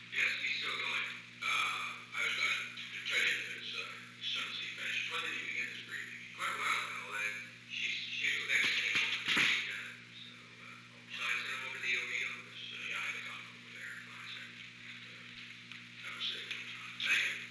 Secret White House Tapes
Conversation No. 917-32
Location: Oval Office
The President met with an unknown man.